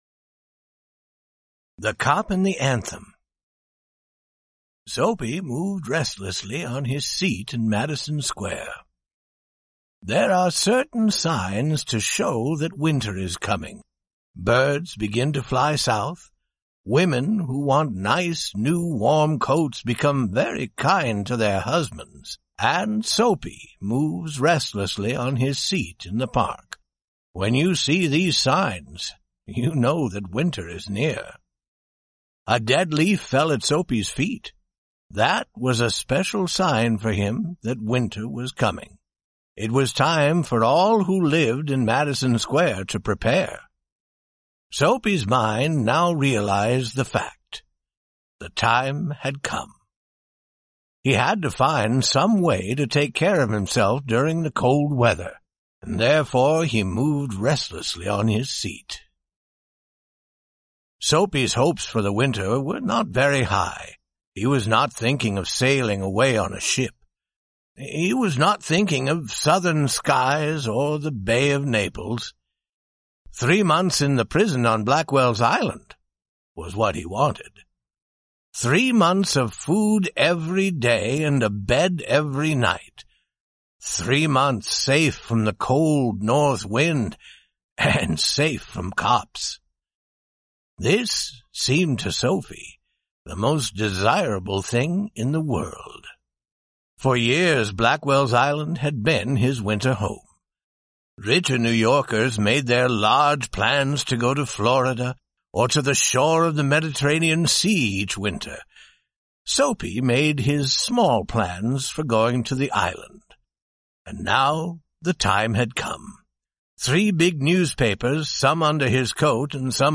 We present the short story "The Cop and the Anthem," by O. Henry. The story was originally adapted and recorded by the U.S. Department of State.